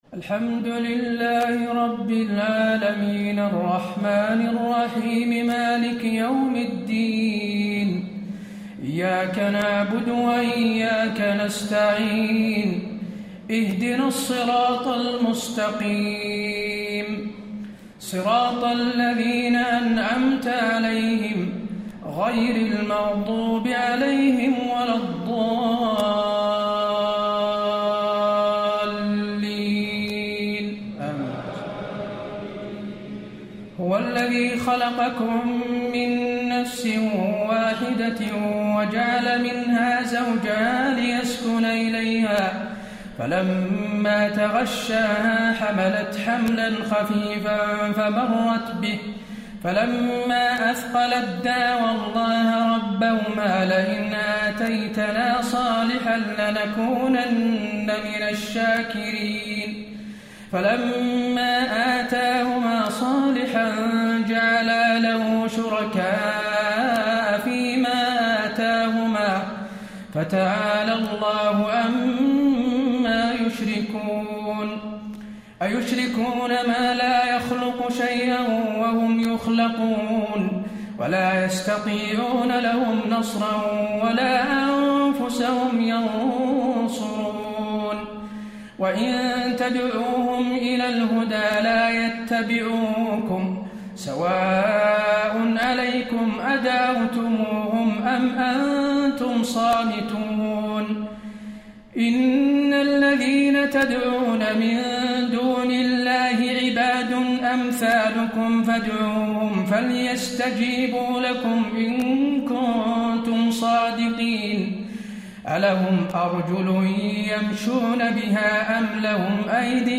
تهجد ليلة 29 رمضان 1435هـ من سورتي الأعراف (189-206) و الأنفال (1-40) Tahajjud 29 st night Ramadan 1435H from Surah Al-A’raf and Al-Anfal > تراويح الحرم النبوي عام 1435 🕌 > التراويح - تلاوات الحرمين